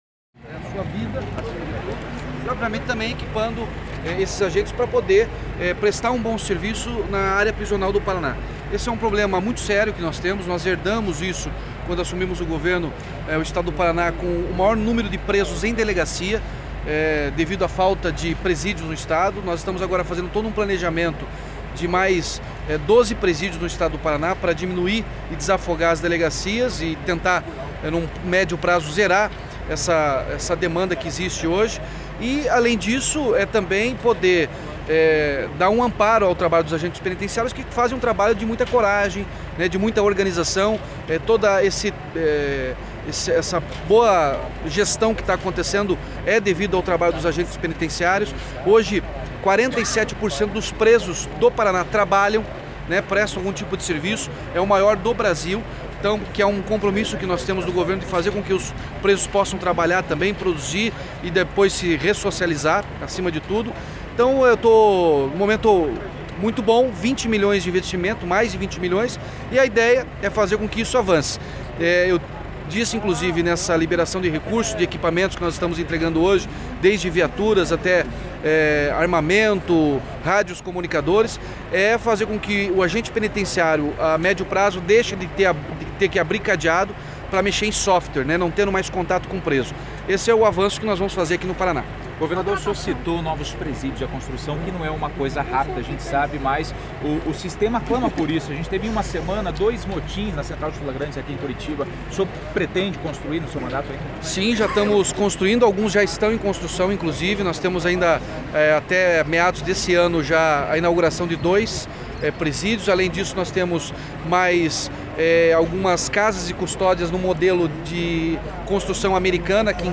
O governador Ratinho Junior afirmou, em entrevista coletiva na manhã desta quarta-feira (8), que 47% dos presos do Paraná prestam algum tipo de serviço nas penitenciárias públicas.
A entrevista foi concedida no Palácio Iguaçu, em Curitiba, quando o governador entregou equipamentos como armas, rádios comunicadores e veículos de escolta ao Depen (Departamento Penitenciário do Paraná).
Entrevista-Ratinho-Junior-Depen.mp3